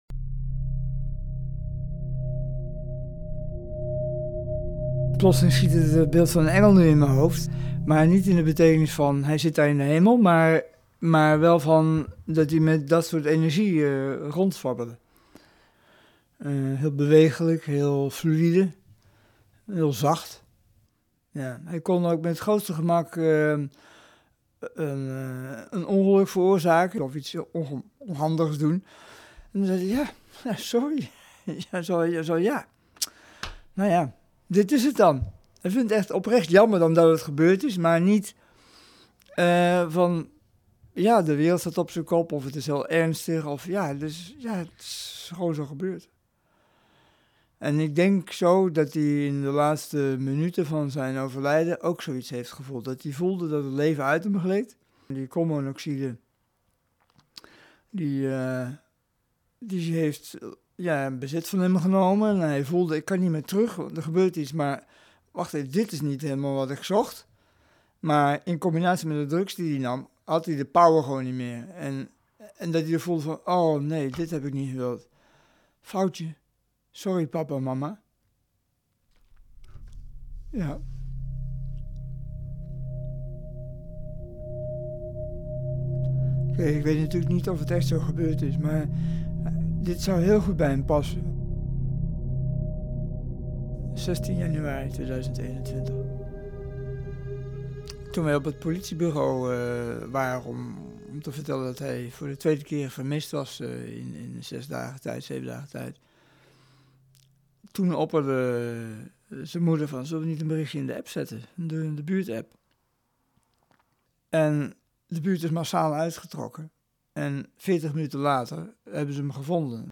Verteller